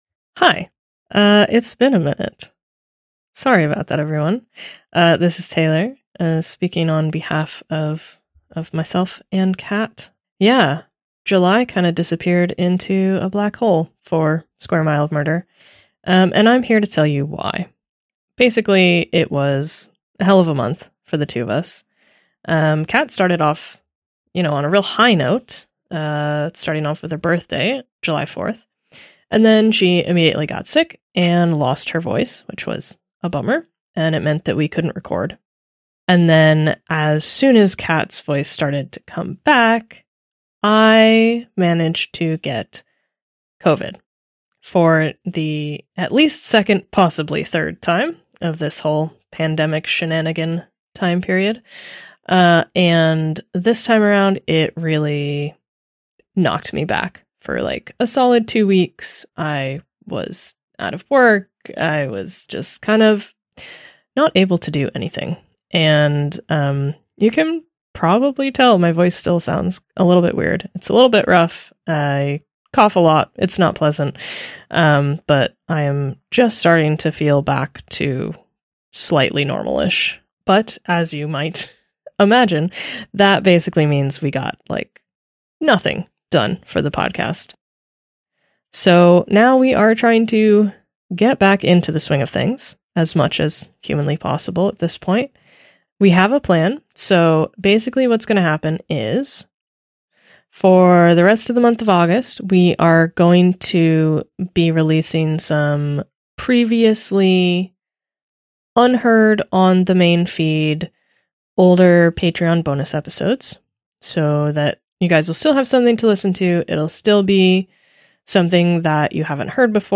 Play Rate Listened List Bookmark Get this podcast via API From The Podcast 1 Square Mile of Murder is a true crime podcast recorded and produced in Glasgow, Scotland.